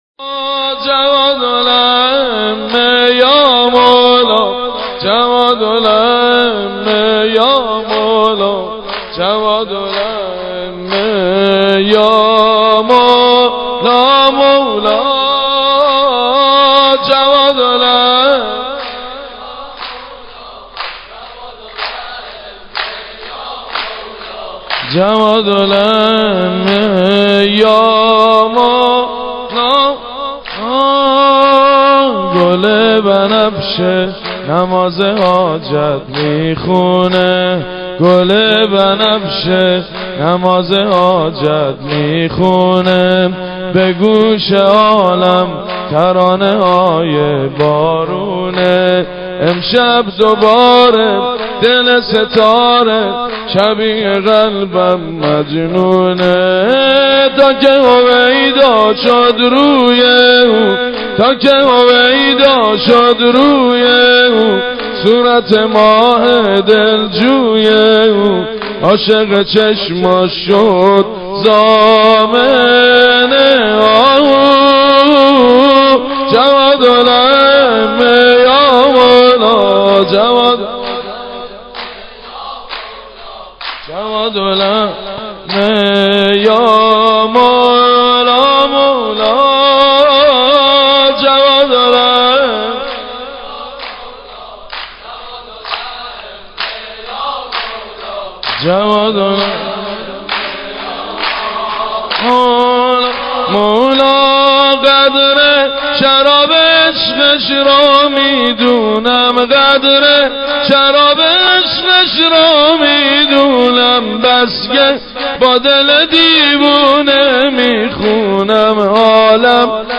صوت/ گلچین مولودی به مناسبت میلاد امام جواد (ع)
به مناسبت میلاد امام جواد(ع) چند مدیحه‌خوانی از مداحان اهل بیت به ساحت ائمه اطهار(ع) و محبان اهل بیت تقدیم می‌شود.